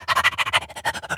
dog_sniff_breathe_03.wav